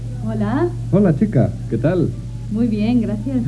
Nu skall vi höra en dialog med dessa två fraser.